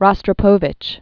(rŏstrə-pōvĭch, rə-strə-), Mstislav Leopoldovich 1927-2007.